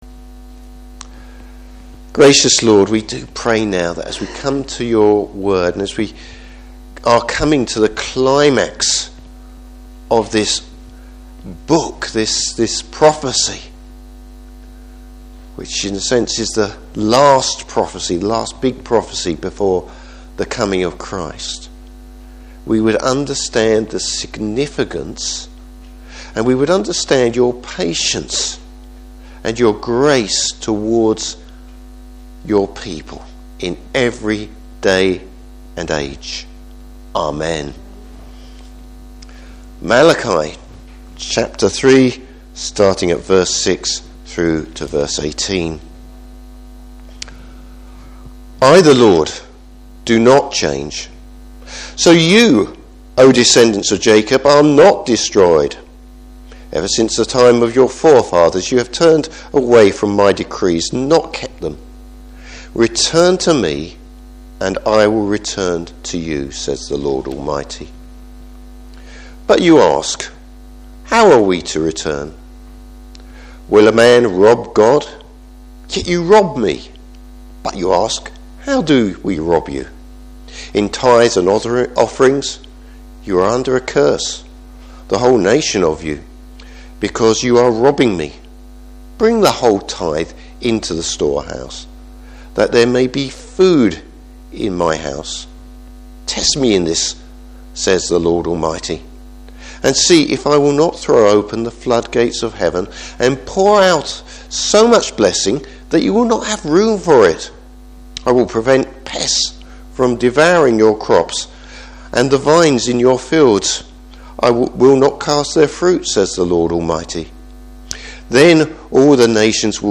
Service Type: Morning Service The Lord’s challenge to his people.